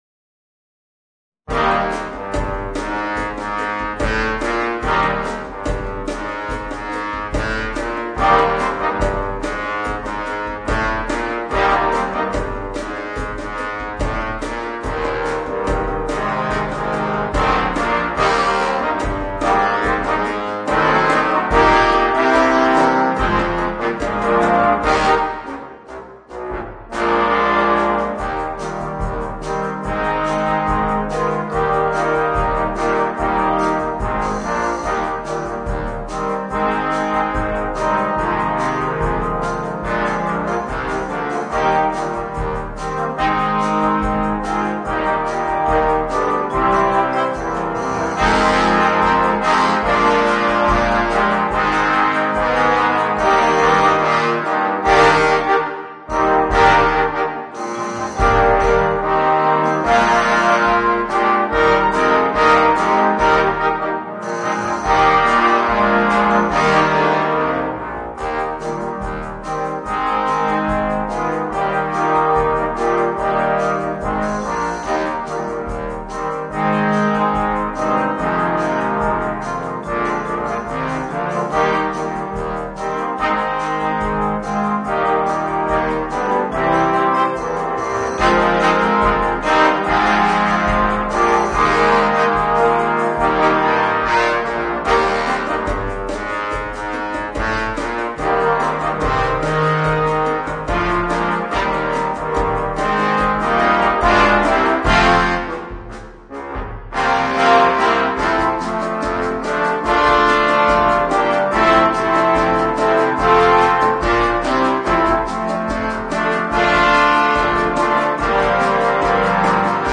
Voicing: 12 Trombones